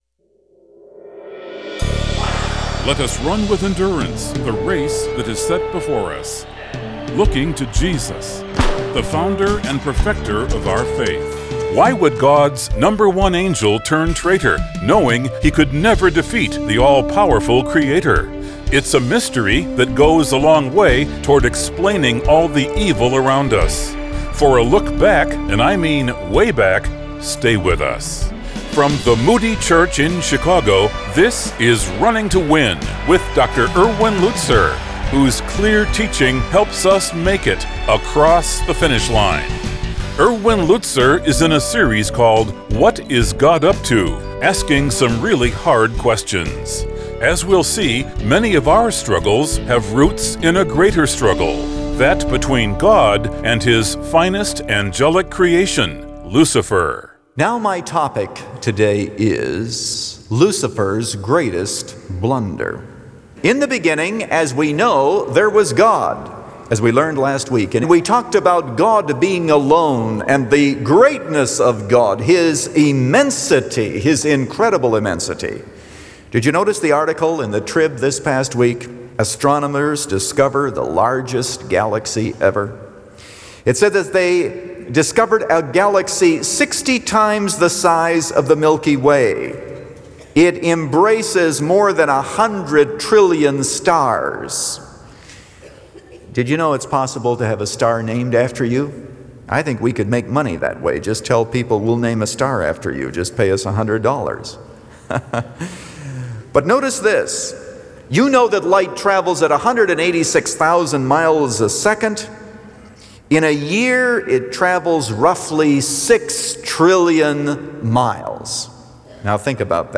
Since 1998, this 15-minute program has provided a Godward focus.